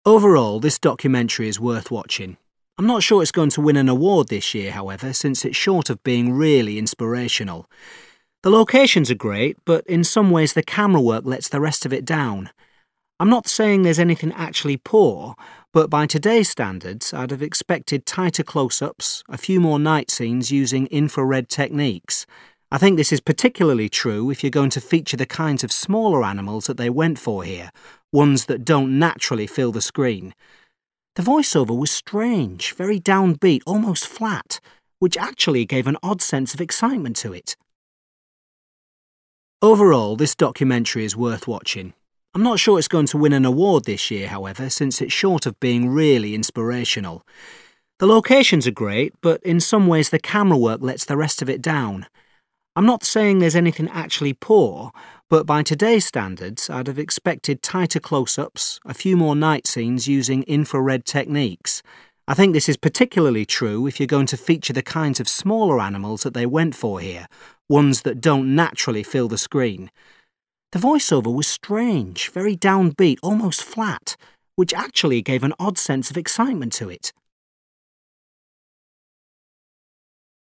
4. You hear a man talking about a wildlife documentary. What aspect of it disappointed him?